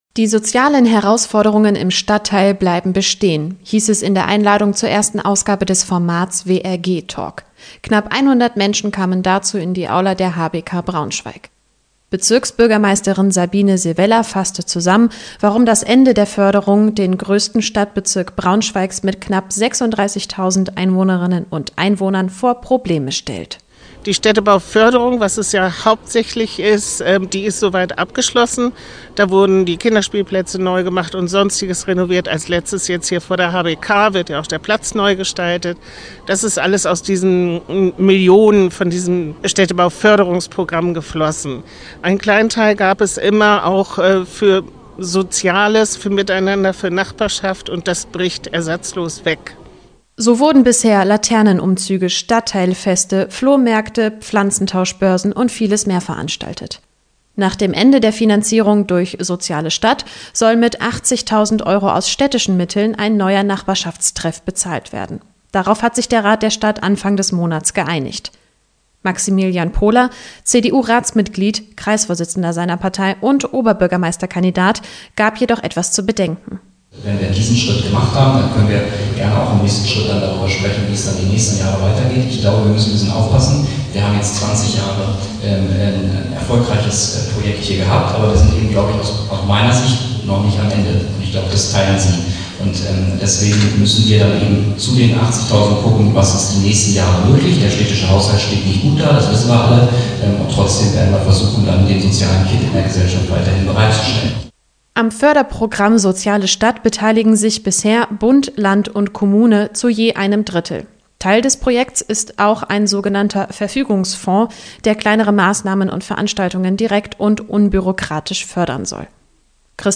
Nach mehr als 20 Jahren endet im Dezember das Förderprogramm „Soziale Stadt“. Damit unterstützen Bund, Land und Kommunen die Quartiersarbeit vor Ort – so auch im Westlichen Ringgebiet von Braunschweig. Wie und in welcher Form es dort nach dem Auslaufen der Förderung weitergehen kann, war gestern Thema bei einem Informationsabend.